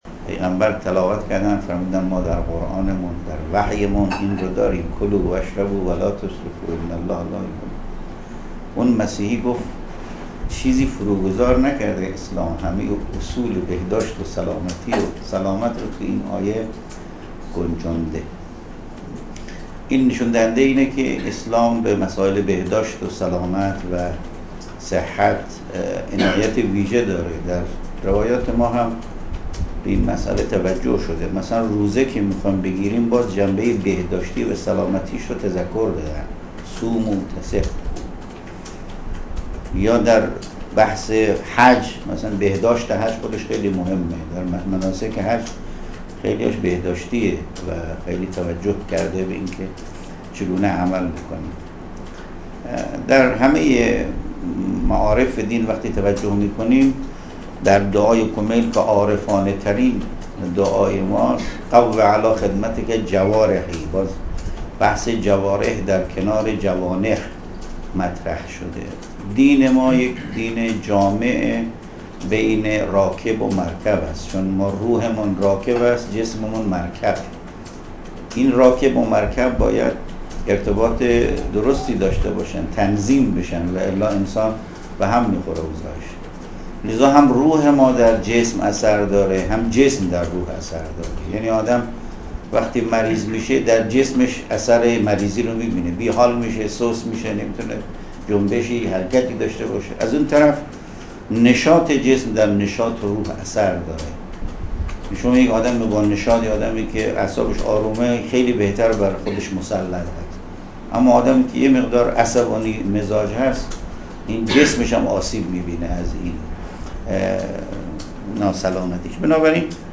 نماینده ولی فقیه در خراسان شمالی:
به گزارش خبرنگار خبرگزاری رسا در خراسان شمالی، حجت‌الاسلام والمسلمین ابوالقاسم یعقوبی، نماینده ولی فقیه در خراسان شمالی و امام جمعه بجنورد، امروز در دیدار سرپرست و مسئولان دانشگاه علوم پزشکی استان، اظهار کرد: اسلام به مسائل بهداشتی و سلامت عنایت ویژه داشته و در روایات ما به این مسئله توجه ویژه‌ای شده است.